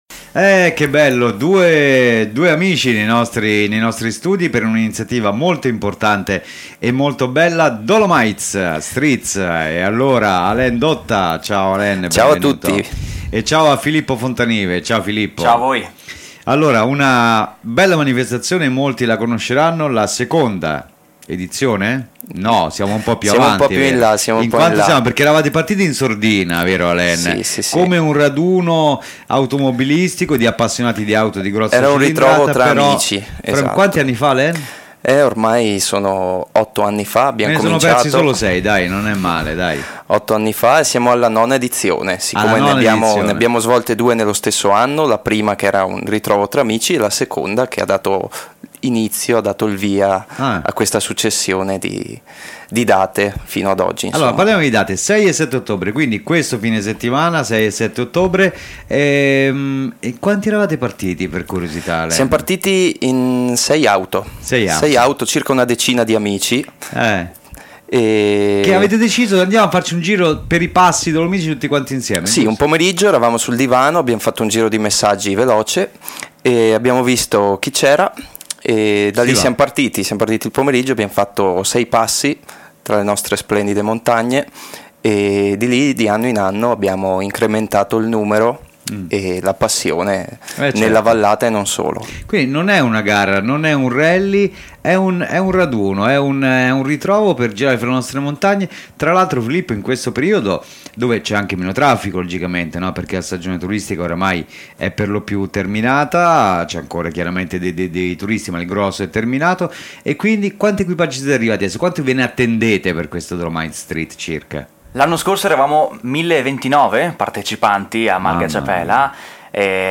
PRESENTAZIONE UFFICIALE A RADIO PIU’, L’INTERVISTA